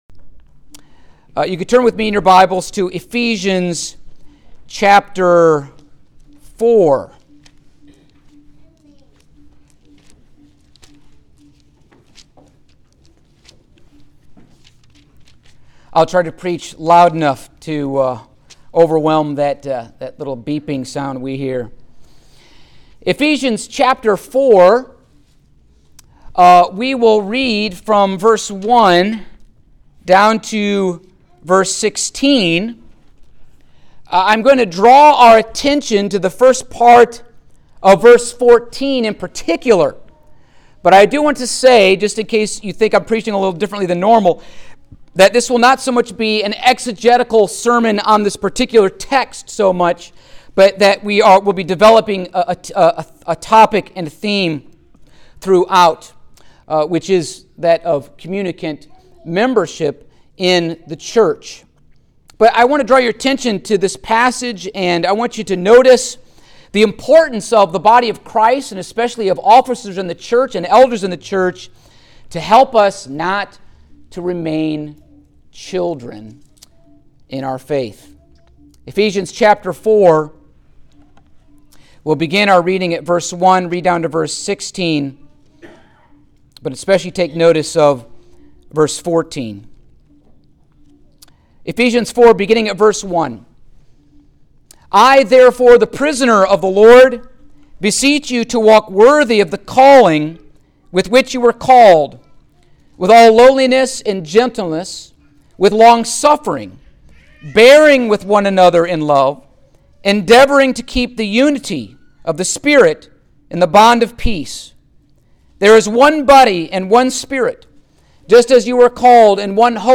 Baptism Sermons
Service Type: Sunday Morning